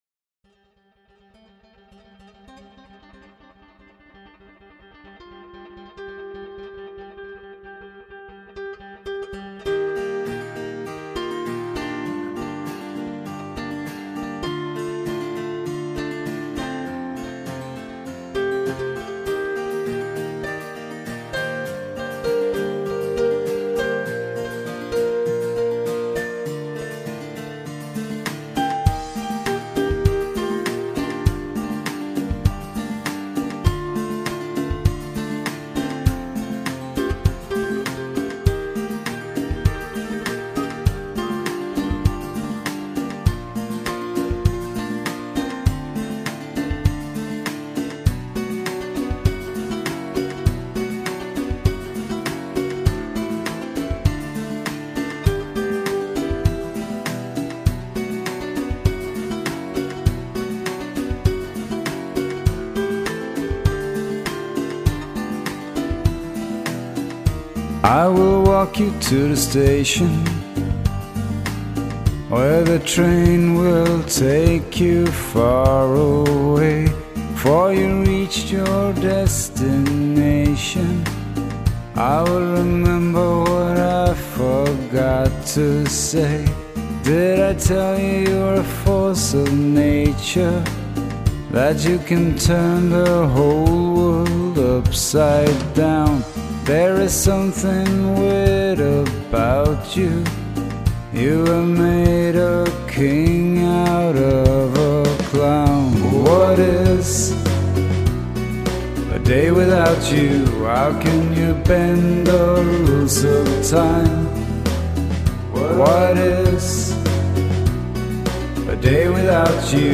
(Pop)